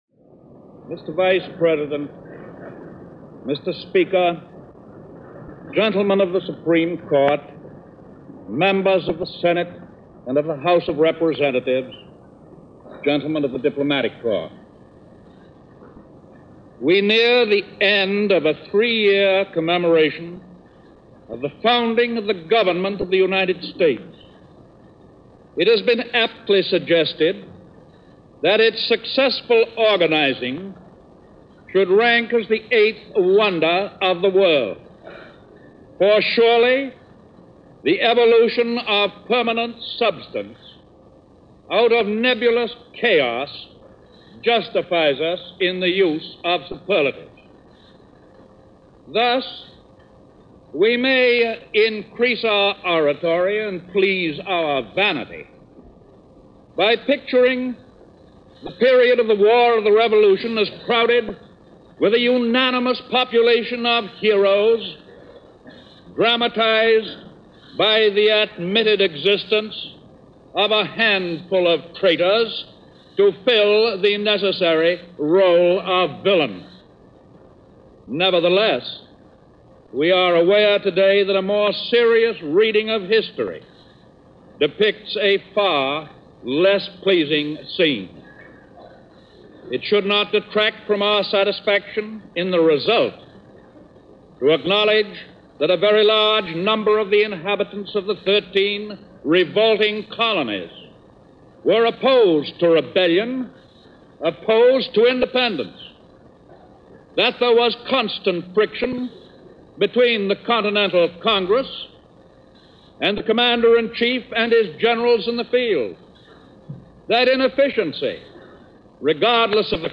Roosevelt Addresses Congress - March 4, 1939 - Annual State Of The Union Address - complete as broadcast on all networks
FDR-Address-to-Congress-March-4-1939.mp3